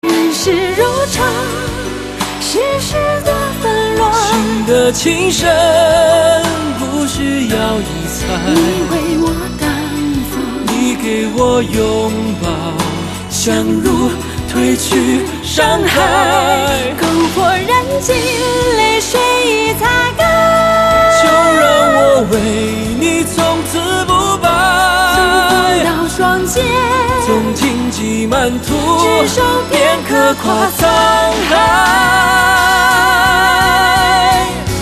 M4R铃声, MP3铃声, 华语歌曲 105 首发日期：2018-05-15 04:46 星期二